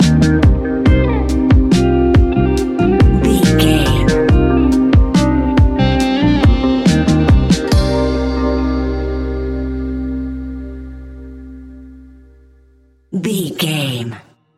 Ionian/Major
C♭
chill out
laid back
sparse
chilled electronica
ambient
atmospheric